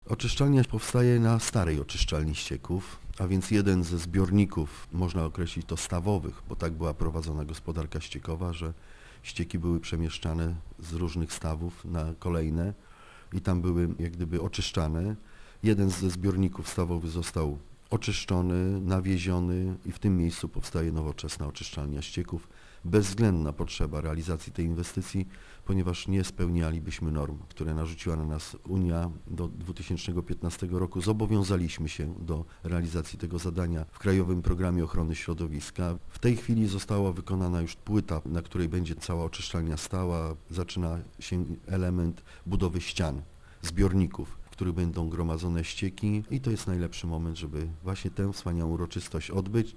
Prace przy budowie obiektu rozpoczęły się pod koniec ubiegłego roku i obecnie są na takim etapie, że możemy zorganizować symboliczna ceremonię - mówi burmistrz Ryk Jerzy Gąska: